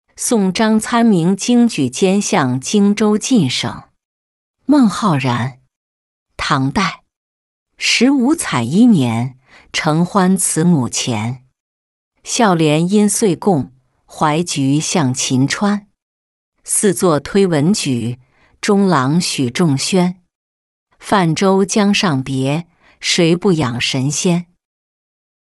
送张参明经举兼向泾州觐省-音频朗读